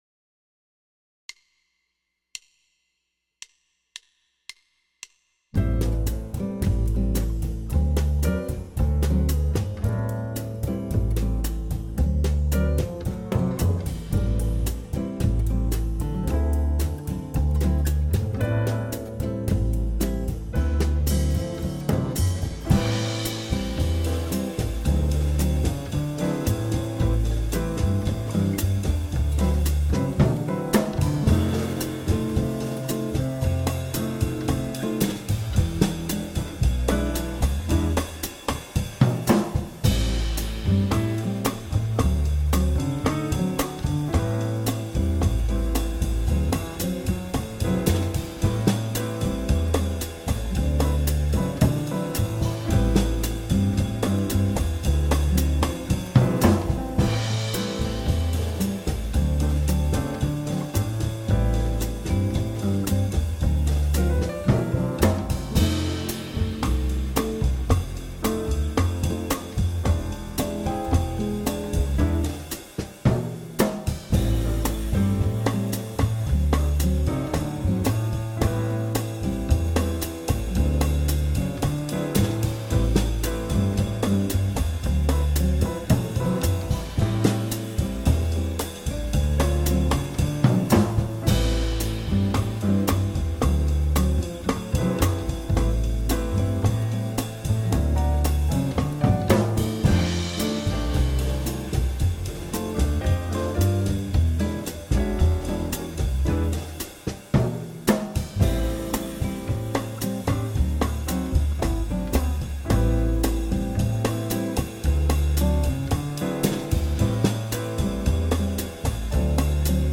Ein Playalong-Track